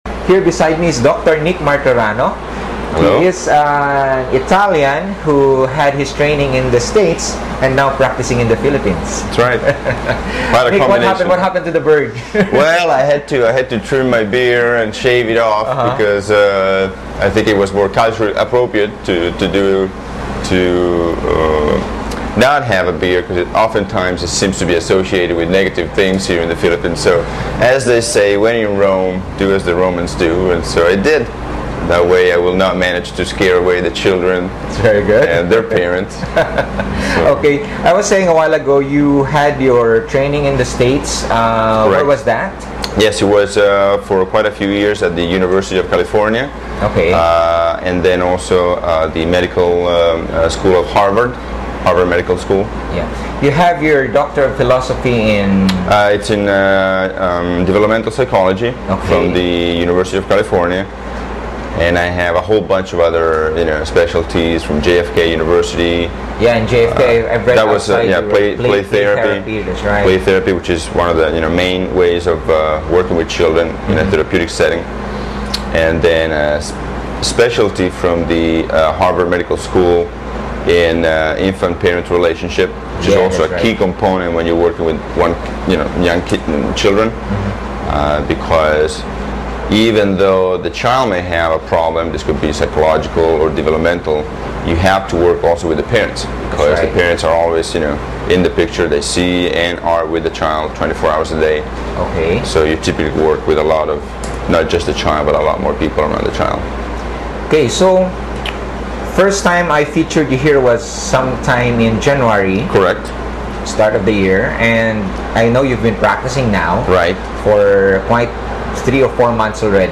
Audio-only version of the TV Interview